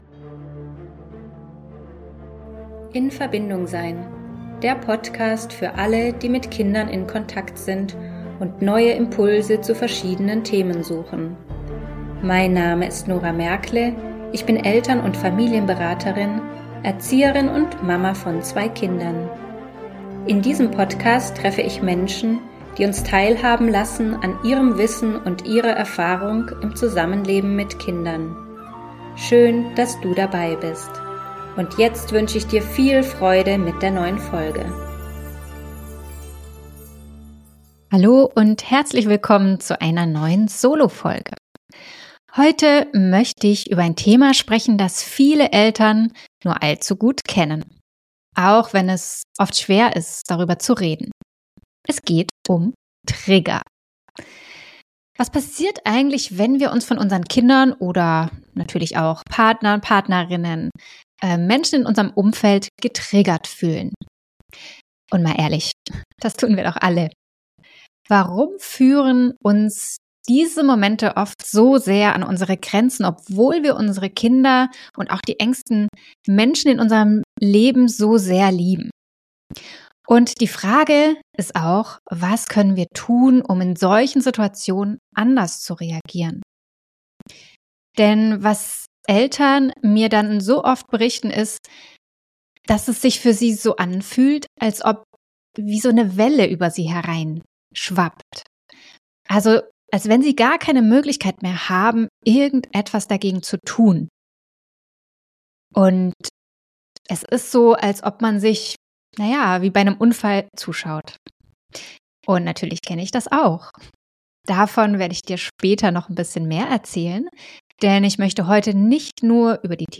Hier kommt wieder eine Solofolge. Diesmal geht es um das Thema Trigger. Warum fühlen wir uns überhaupt getriggert und was kann helfen, mehr Leichtigkeit und Gelassenheit in den Alltag einzuladen?